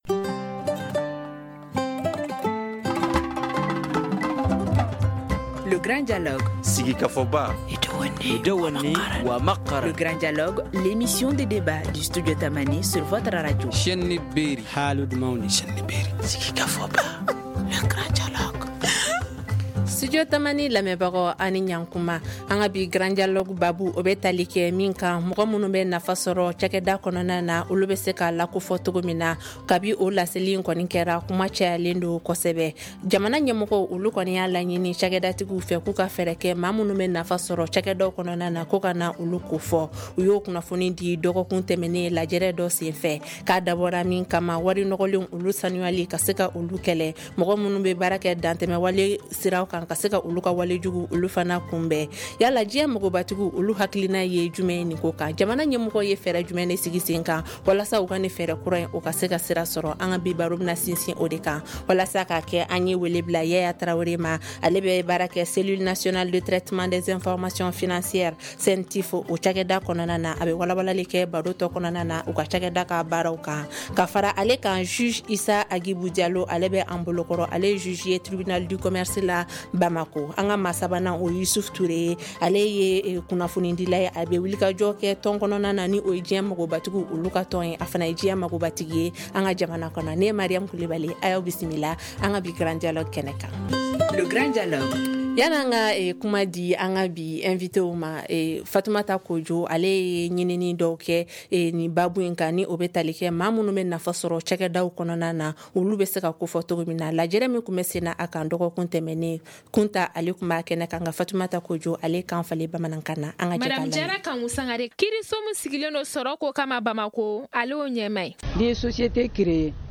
On vous dit tout dans ce débat
Membre de la Cellule Nationale De Traitement Des Informations Financières (CENTIF)